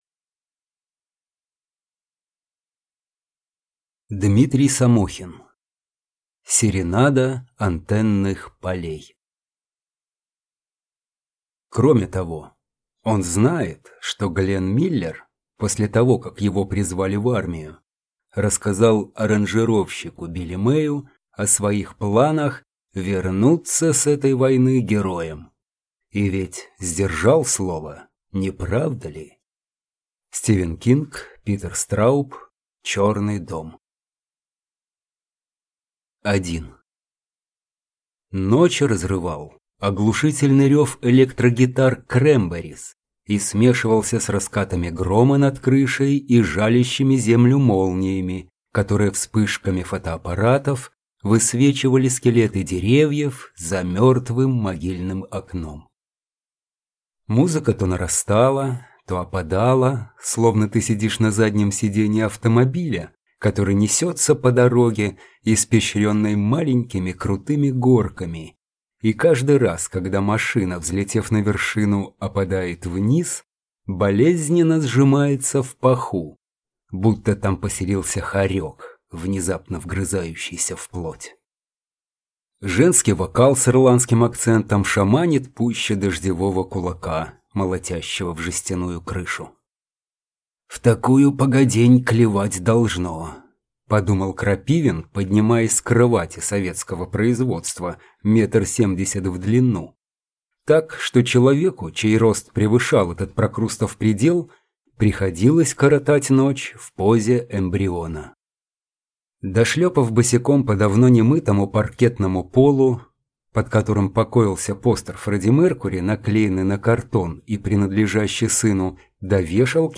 Аудиокнига Серенада антенных полей | Библиотека аудиокниг